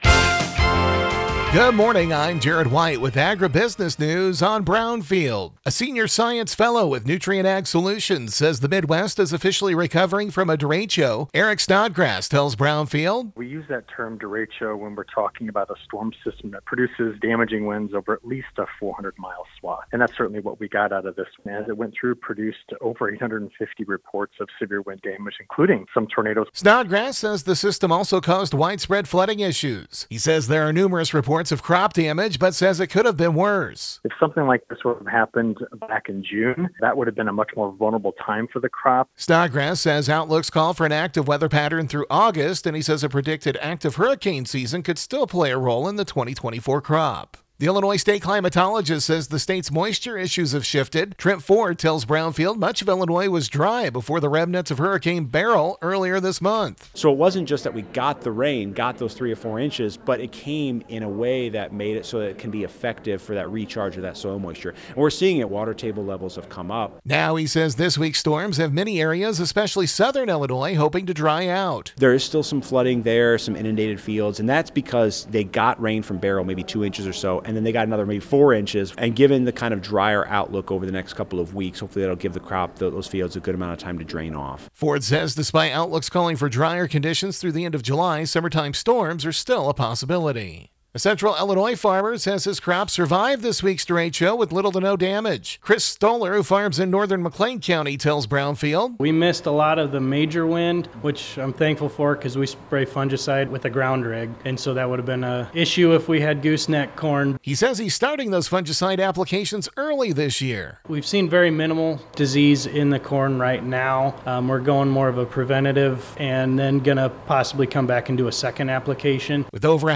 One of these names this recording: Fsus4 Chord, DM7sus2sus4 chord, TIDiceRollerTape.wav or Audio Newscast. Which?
Audio Newscast